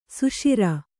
♪ suṣira